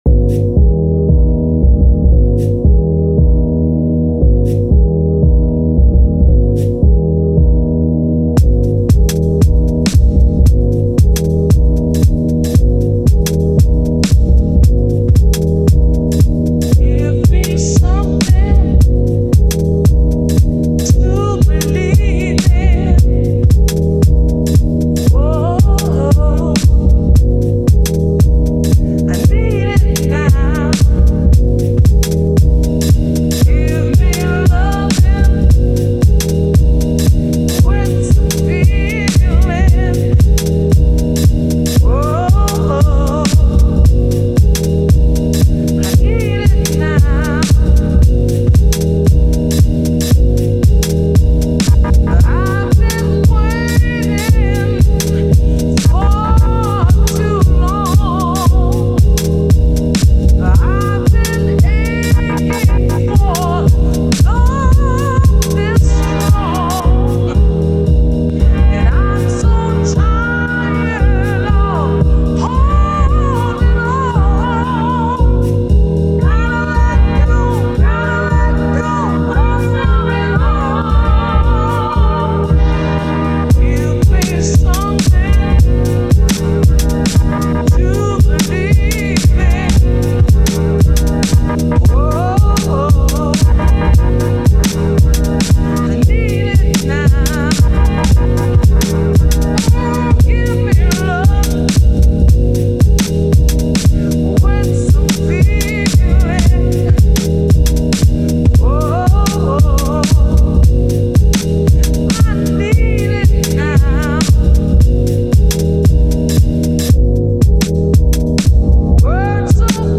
I’ve gone sort of soulful house remix.